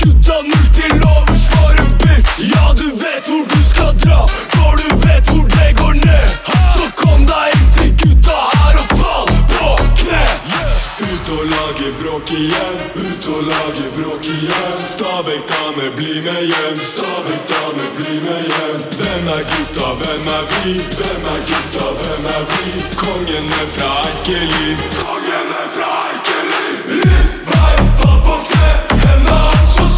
Жанр: Хаус